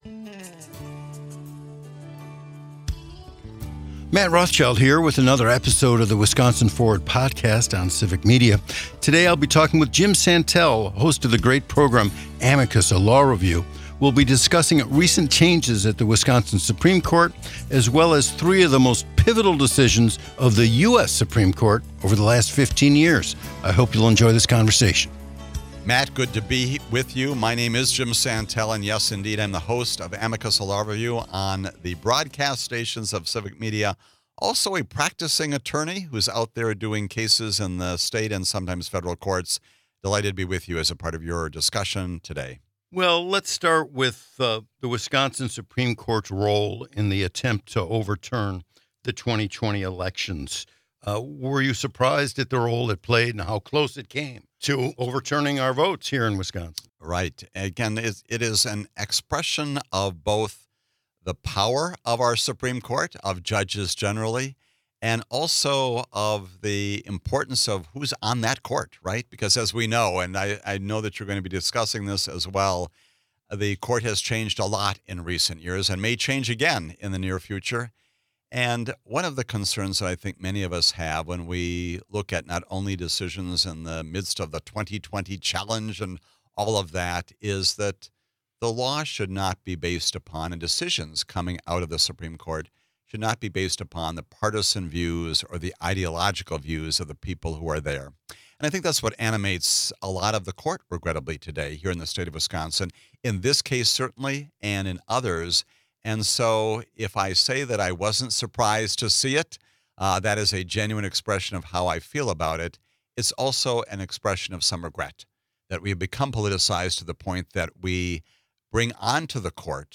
a conversation about the legal landscape in Wisconsin and the nation.